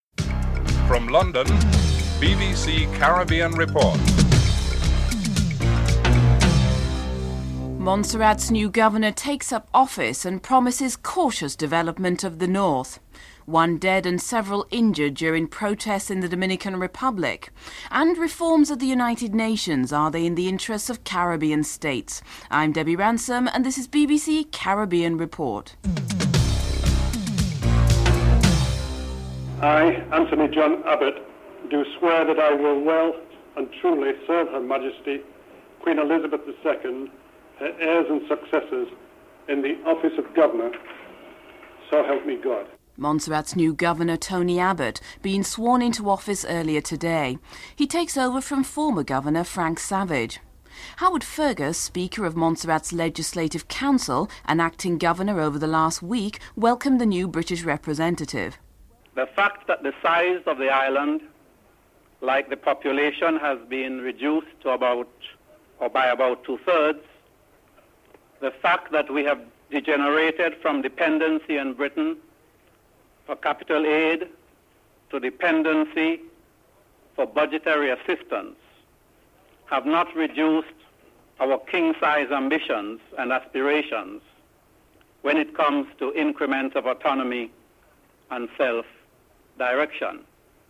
1. Headlines (00:00-00:29)
Governor of Montserrat, Sir Anthony John Abbott, Acting Governor Howard Fergus, Acting Chief Minister Adelina Tuitt and Chief Minister David Brandt are interviewed (00:30-07:37)